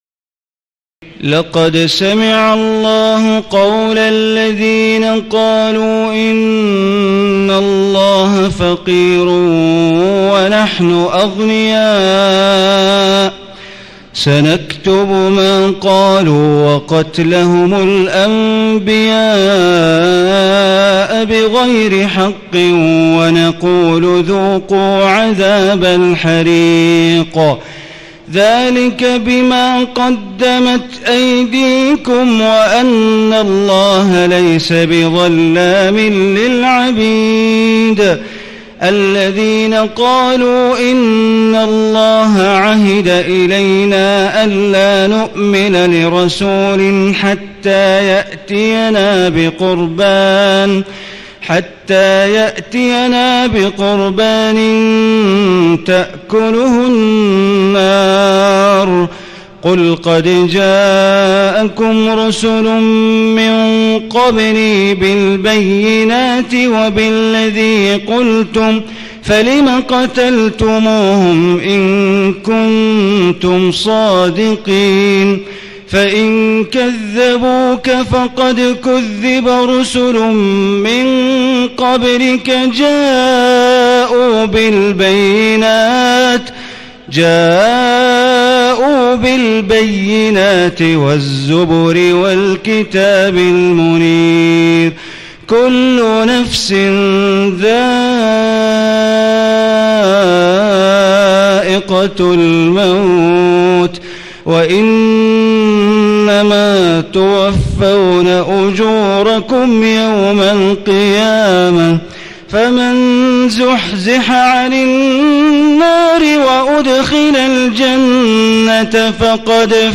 تهجد ليلة 24 رمضان 1436هـ من سورتي آل عمران (181-200) و النساء (1-24) Tahajjud 24 st night Ramadan 1436H from Surah Aal-i-Imraan and An-Nisaa > تراويح الحرم المكي عام 1436 🕋 > التراويح - تلاوات الحرمين